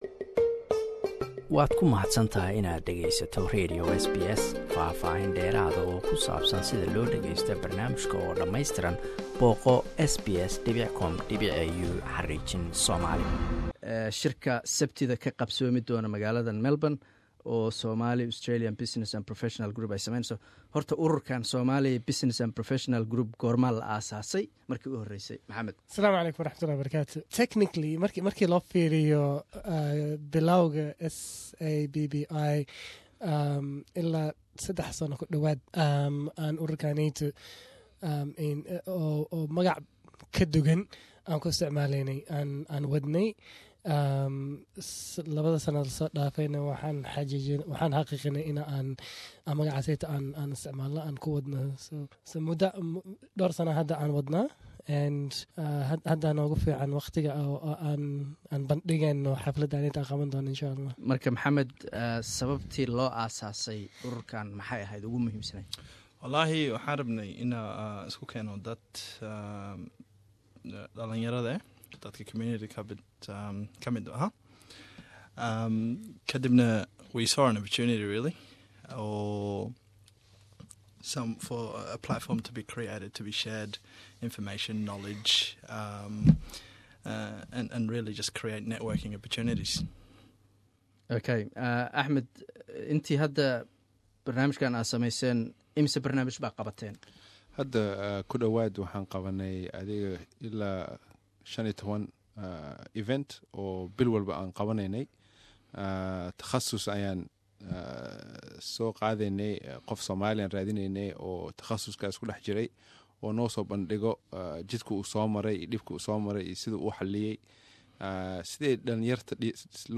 Interview with SABPI conference organizers and guests
Interview with SABPAI conference organizers.